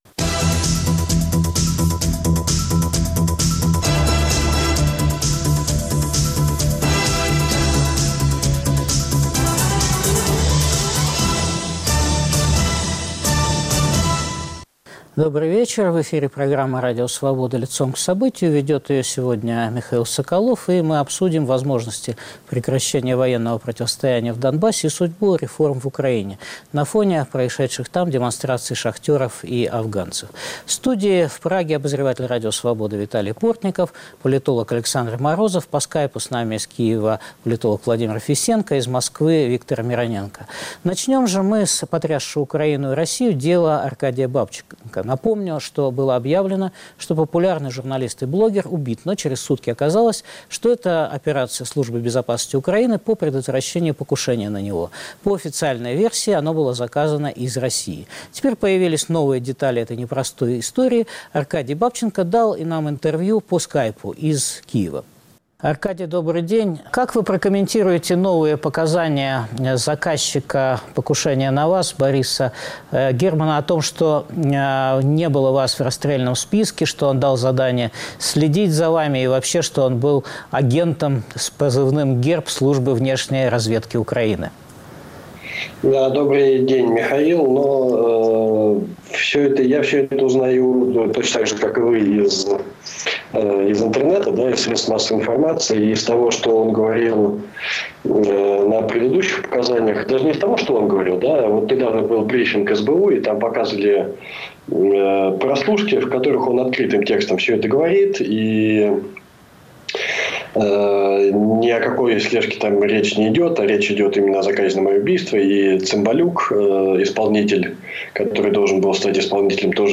Интервью Аркадия Бабченко.